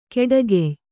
♪ keḍagey